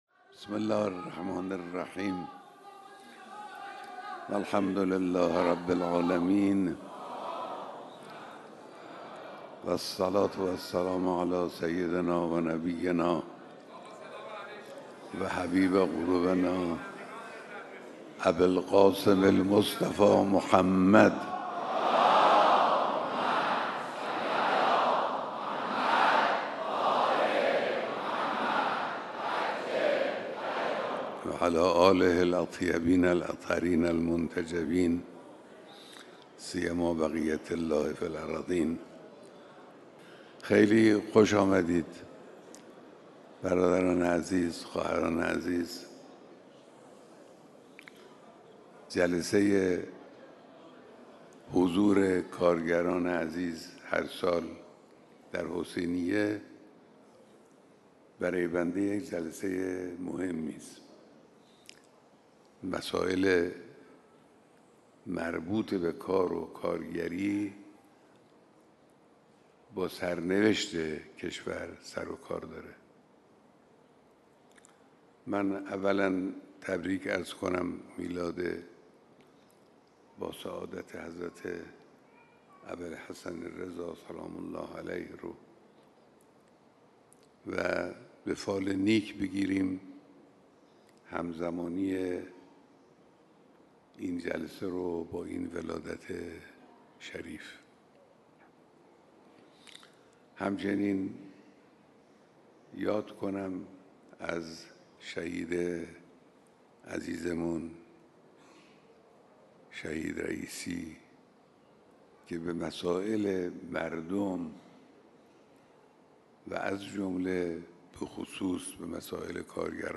بیانات در دیدار هزاران نفر از کارگران سراسر کشور